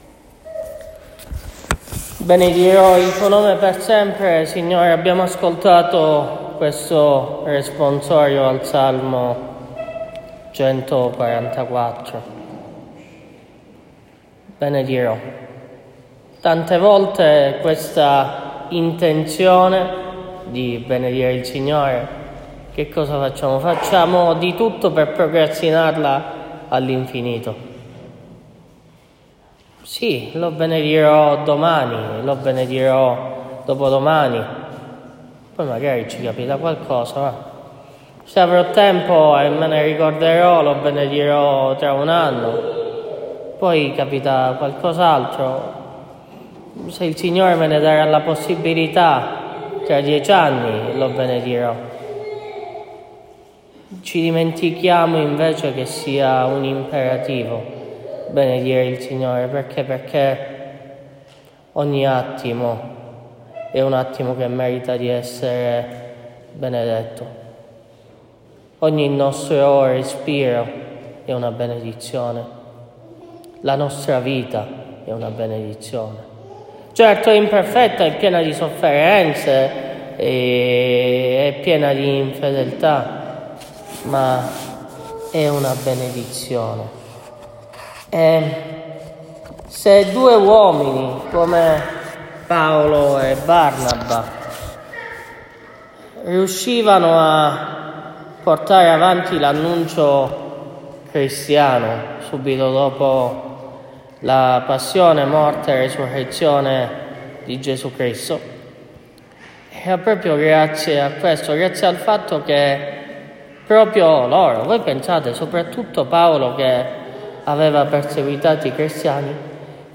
Omelia della V domenica di Pasqua